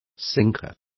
Also find out how plomo is pronounced correctly.